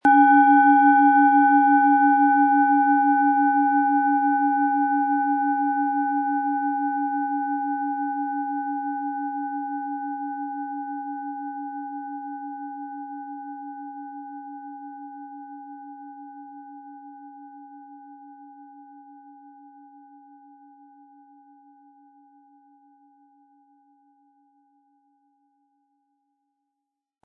Planetenton
Von Hand hergestellte Klangschale mit dem Planetenton Merkur.
MaterialBronze